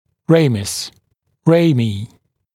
[ˈreɪməs] [ˈreɪmiː][ˈрэймэс] [ˈрэйми:]ветвь (ветви) (нижней челюсти)